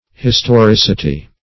historicity - definition of historicity - synonyms, pronunciation, spelling from Free Dictionary